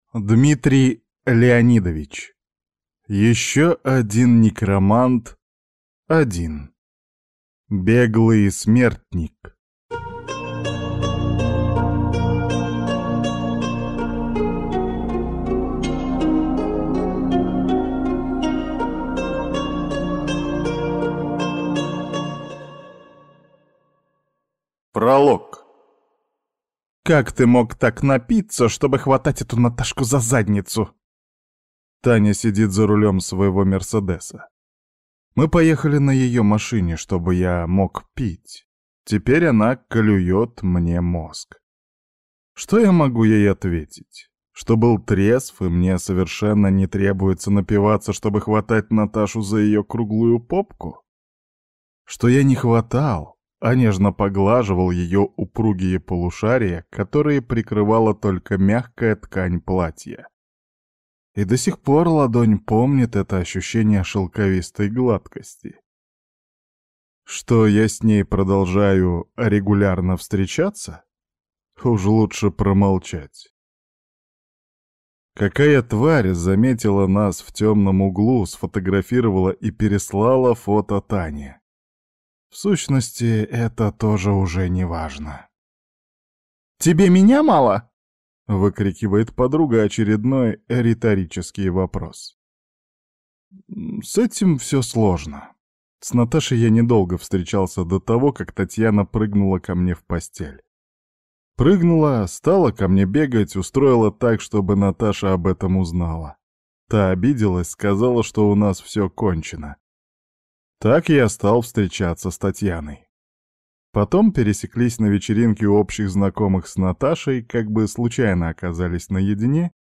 Аудиокнига Еще один некромант 1. Беглый смертник | Библиотека аудиокниг